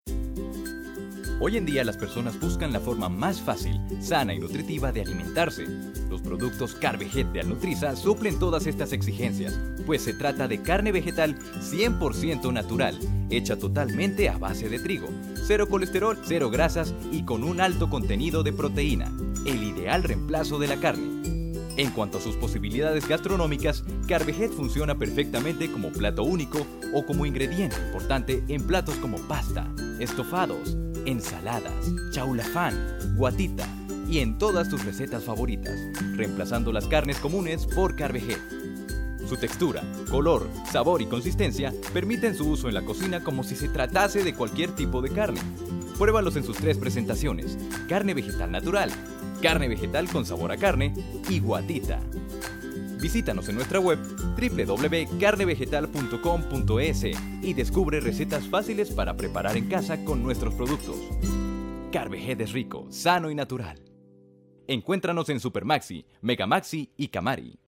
Spanish - Latin American Neutral, Smooth and professional for presentations. Multifaceted for talents in commercials. Professional for announcer. Warm for Narrations. Multifaceted since young male till midle elegant male for voice over. a lot of voices, a lot of characters. Comic, fun. Versatil
Sprechprobe: Sonstiges (Muttersprache):